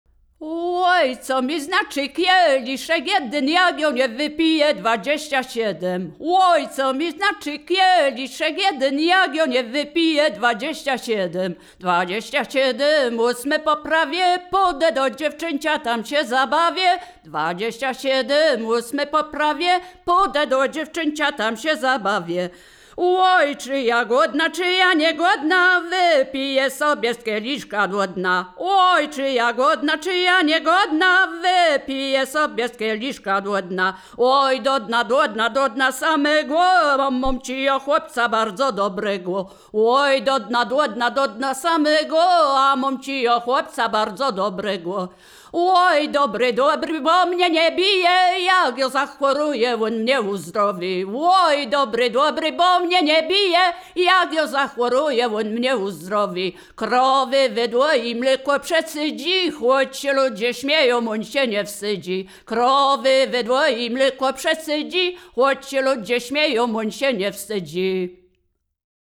województwo wielkopolskie, powiat gostyński, gmina Krobia, wieś Posadowo
liryczne miłosne żartobliwe przyśpiewki